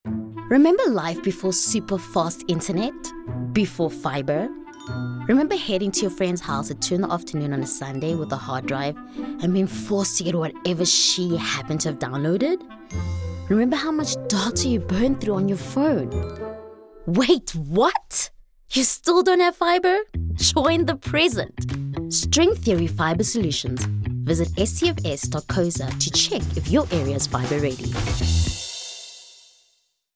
Professional, broadcast-ready voice-overs delivered in both English and French.
Commercial (English)